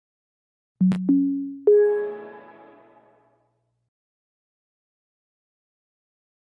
Windows-11-Startup-sound.mp3